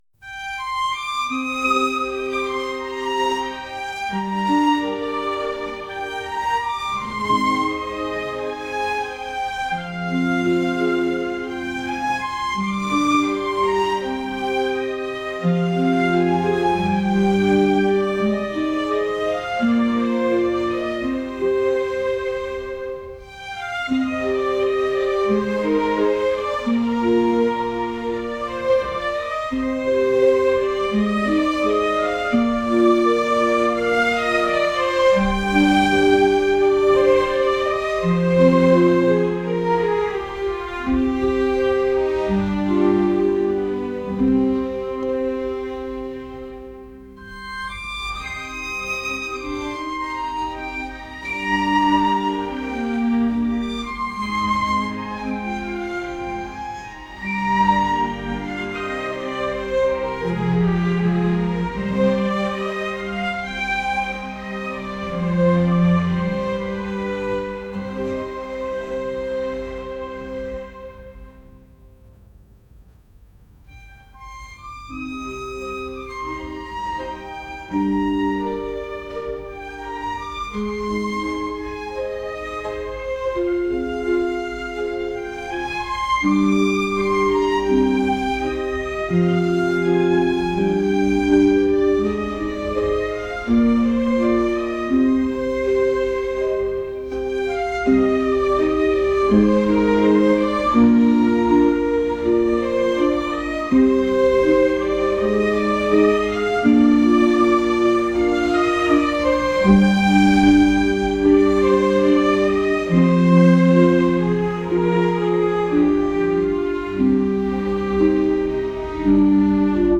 classical | cinematic | ambient